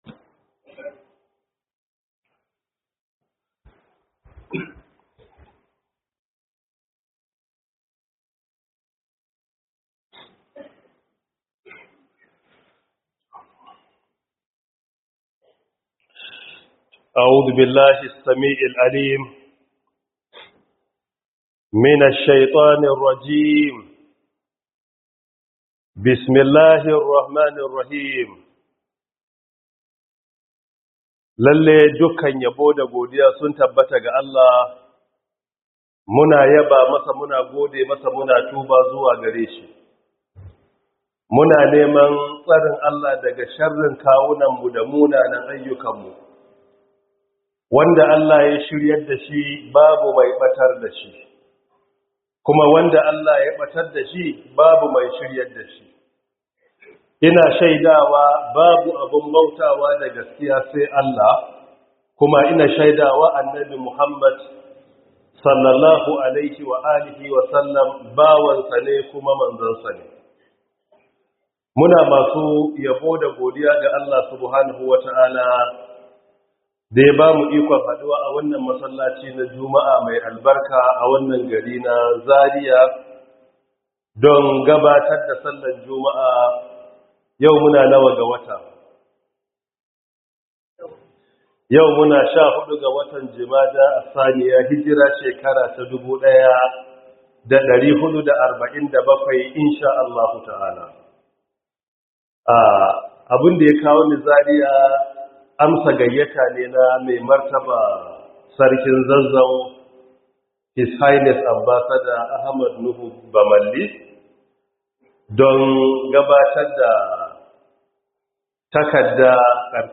Cikan Imani - 2025-12-05 - Huduba by Prof. Isah Ali Pantami